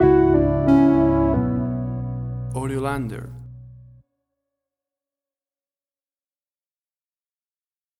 Airy industrial tension music.
Tempo (BPM): 90